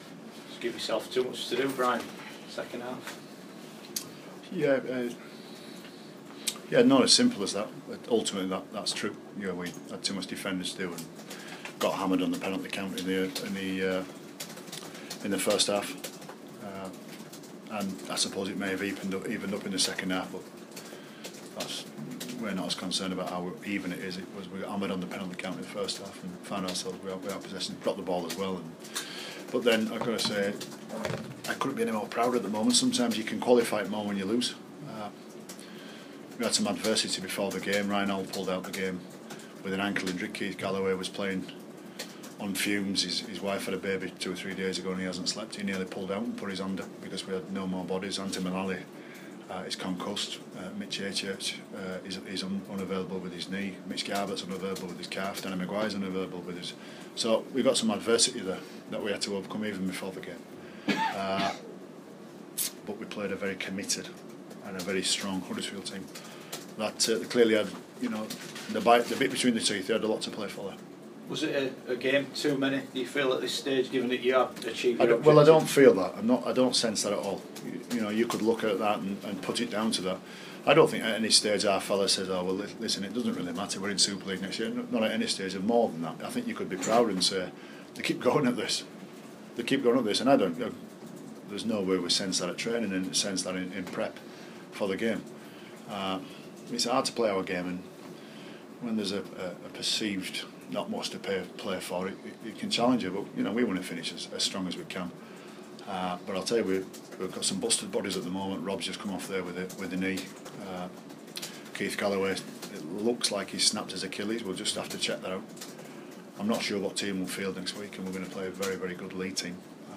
The Leeds Rhinos coach reacts to defeat at Huddersfield